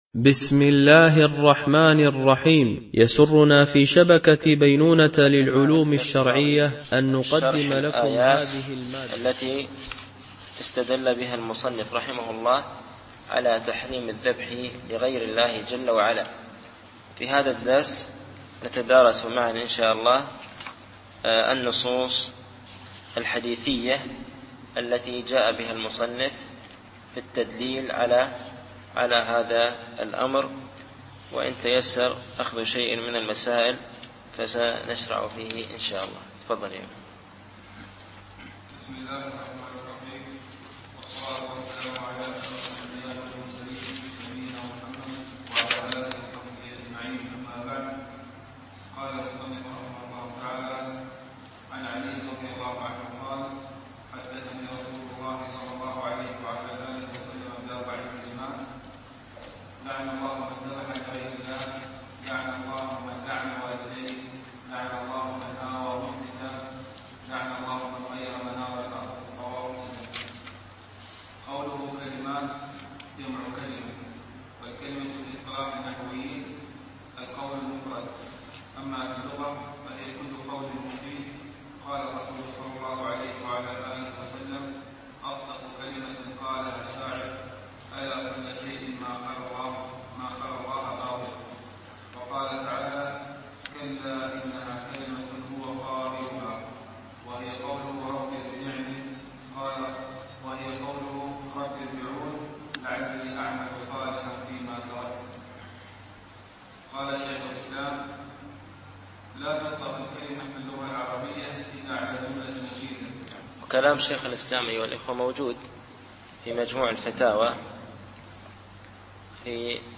التعليق على القول المفيد على كتاب التوحيد ـ الدرس الثامن و العشرون